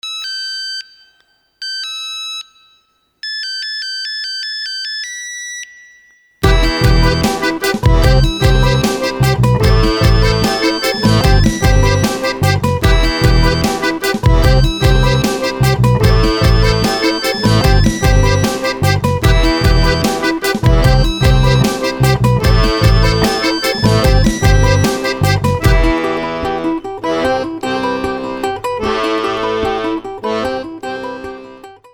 Рингтоны без слов
Рингтоны техно
Аккордеон
Инструментальные